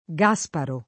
Gaspare [g#Spare] pers. m.; f. (raro) ‑ra — ant. Gasparo [